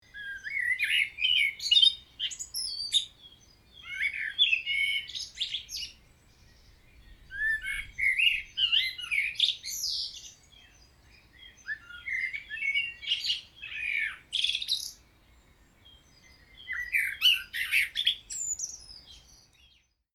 Der Amsel Gesang unterscheidet sich von vielen anderen Vogelstimmen durch seine abwechslungsreichen und klaren Töne.
Die Amsel singt besonders gerne in den frühen Morgenstunden und in der Dämmerung.
Amsel Morgengesang
Amsel-Morgengesang-Wildtiere-in-Europa.mp3